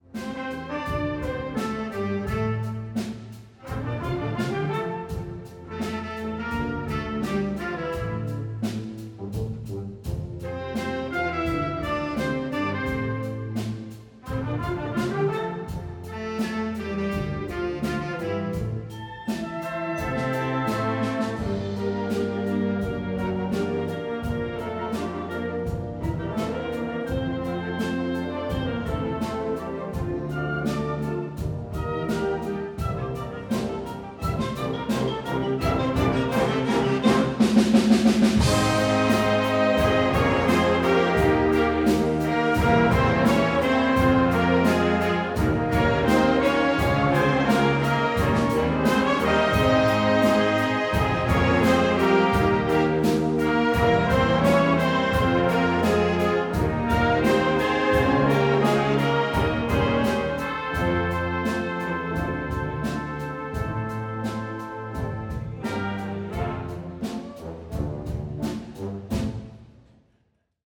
Herbstkonzert 2024